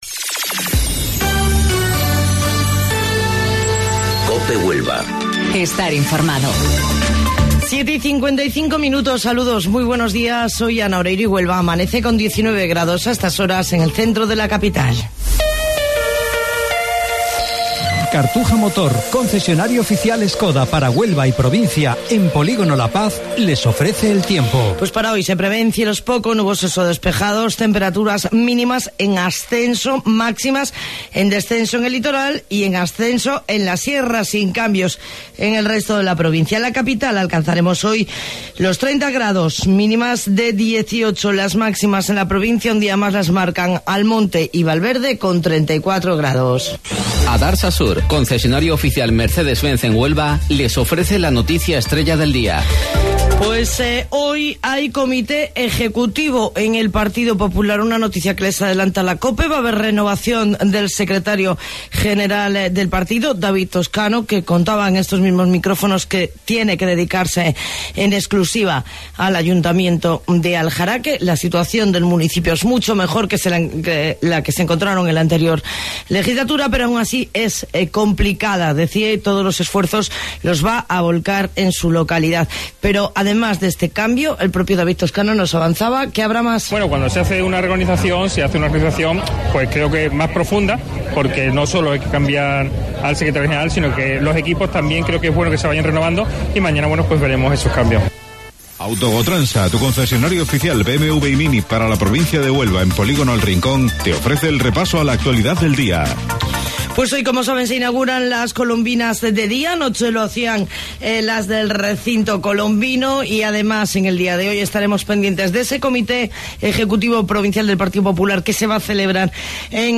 AUDIO: Informativo Local 07:55 del 31 de Julio